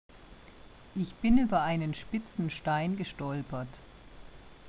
Standardaussprache
Sp– und st– im Anlaut, z.B. in Sport und Strand, aber auch in Zusammensetzungen wie in Schneesturm werden mit dem Zischlaut sch [ ʃ ] gesprochen.
Süddeutschland.wma